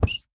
rabbit_hurt2.ogg